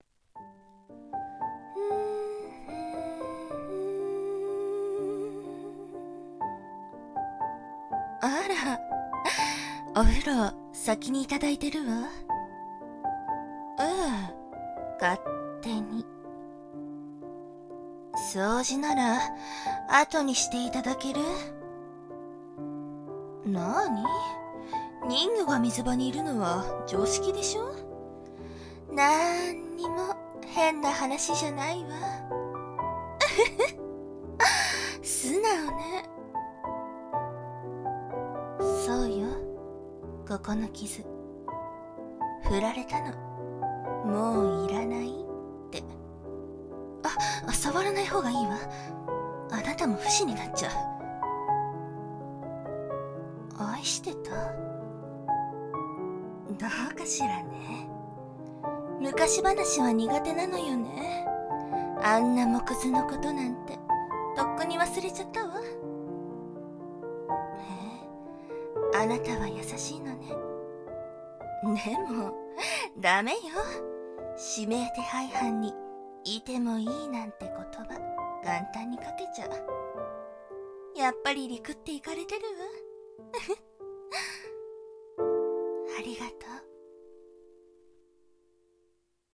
【声劇】人魚とシェアハウス